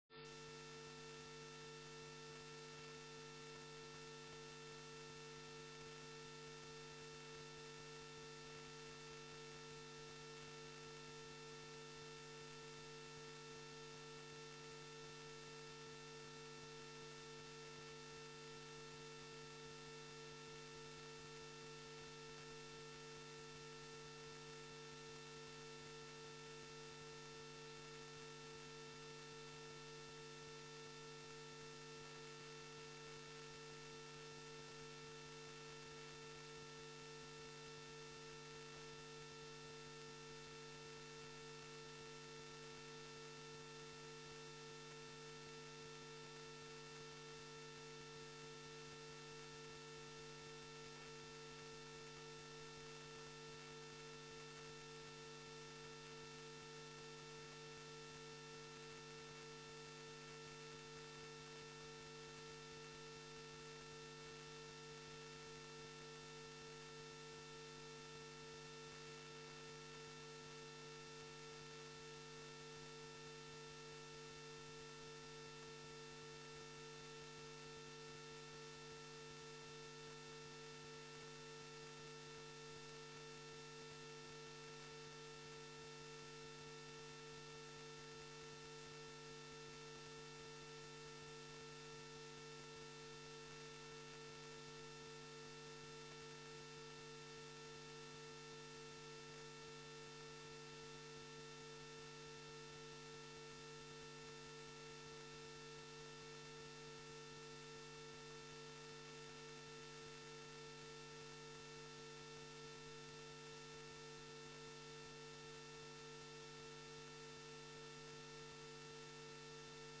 Locatie: Commissiekamer 2